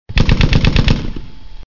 fusil.mp3